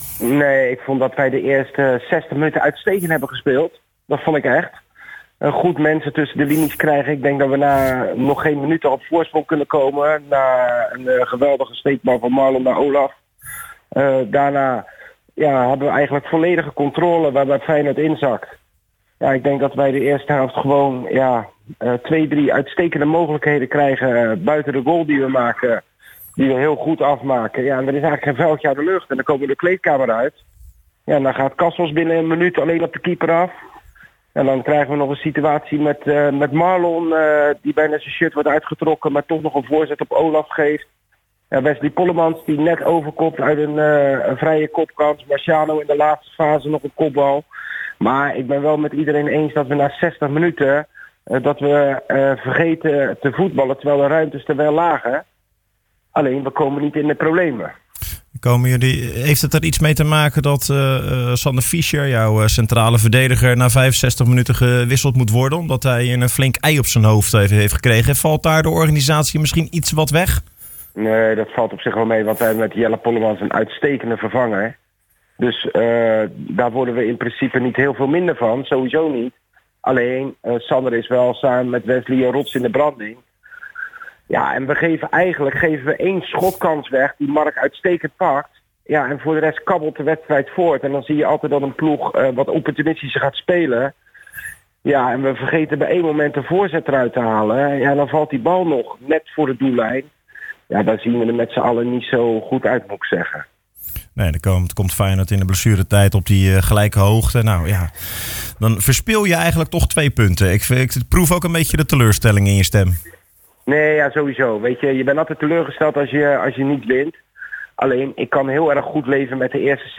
In de IJssel-Sport uitzending